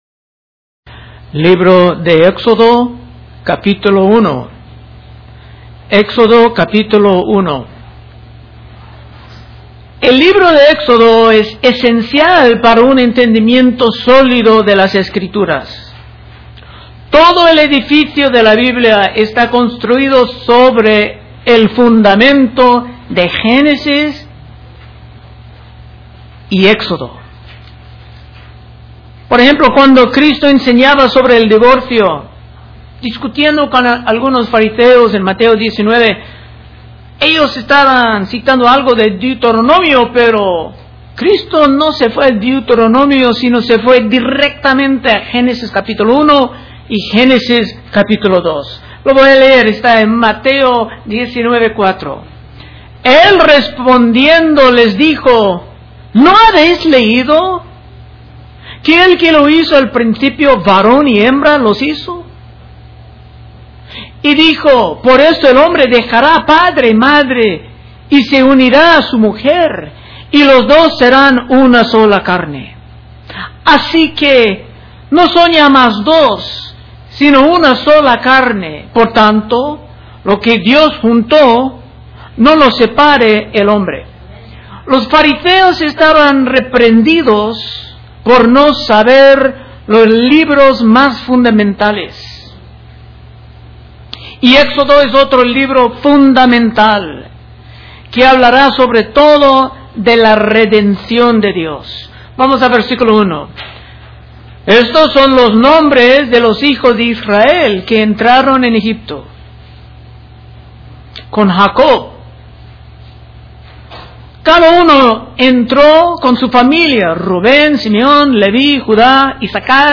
Predicaciones De Exposición Libro De Éxodo